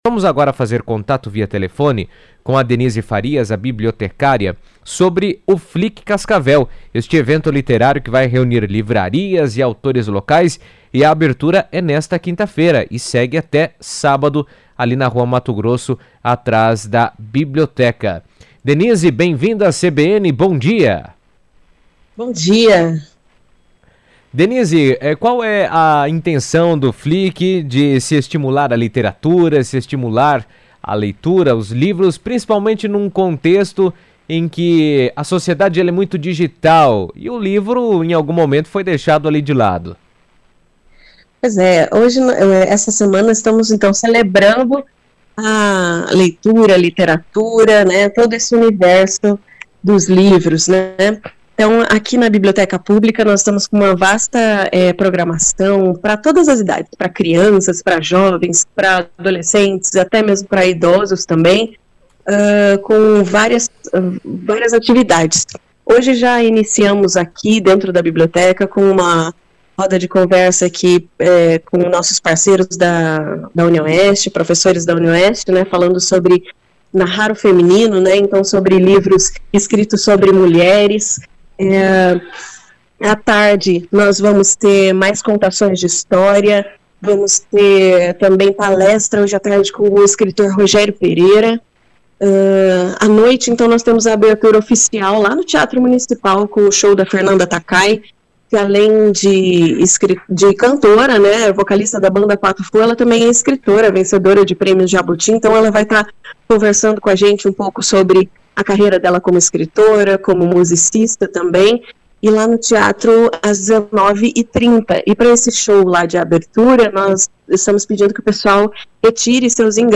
Em ligação à CBN